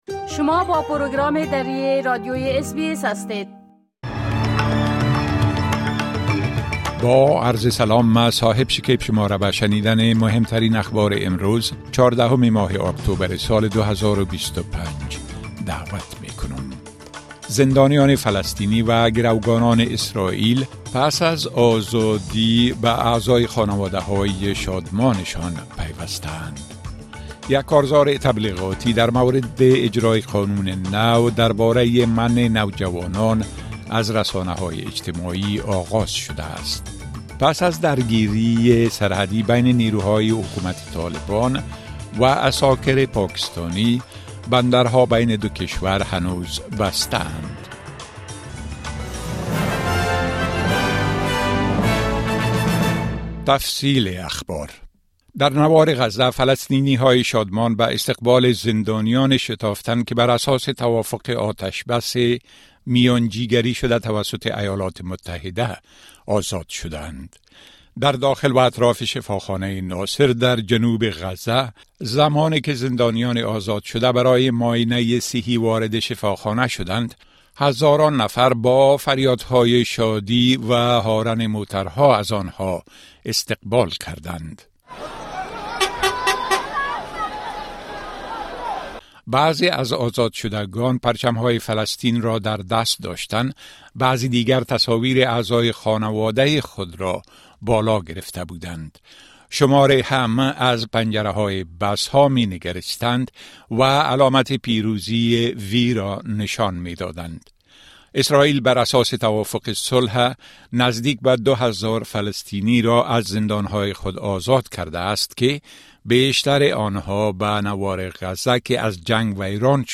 مهمترين اخبار روز از بخش درى راديوى اس بى اس